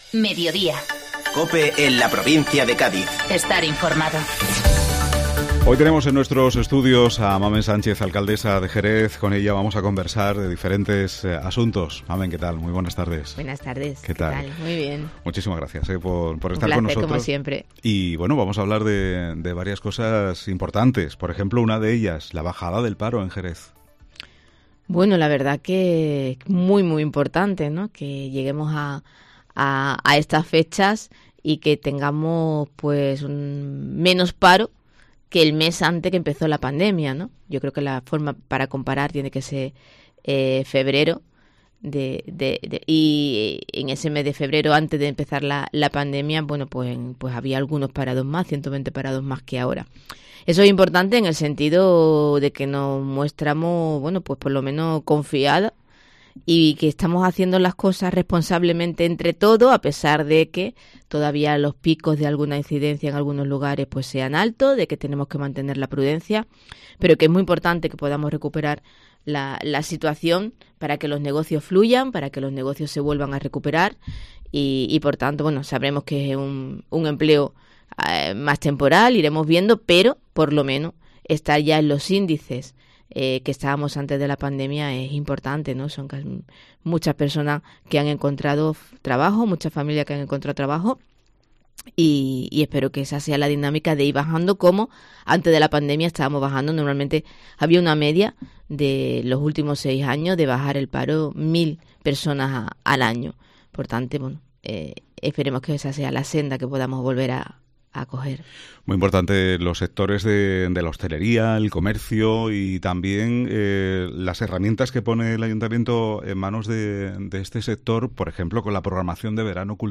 Entrevista Mamen Sánchez, Alcaldesa de Jerez 05 de agosto 2021